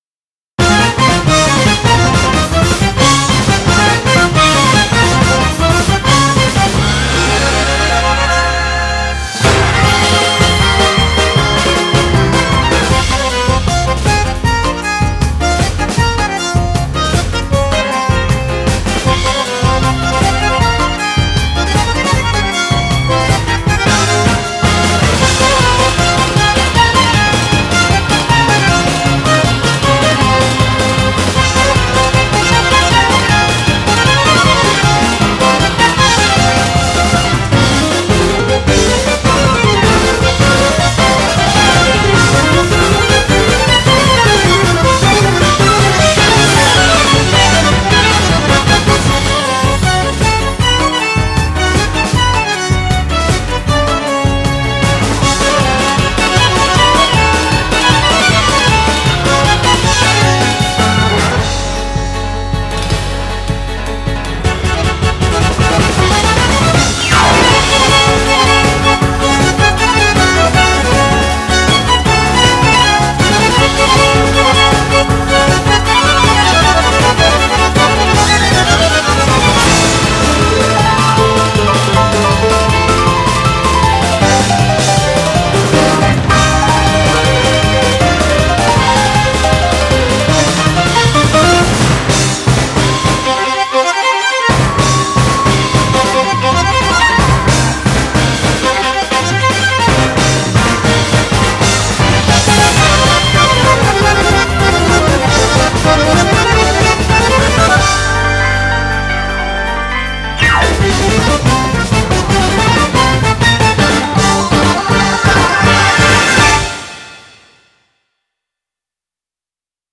BPM156-162
Audio QualityPerfect (High Quality)
It has subtle BPM changes, watch out for those.